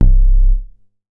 MoogLoRess 011.WAV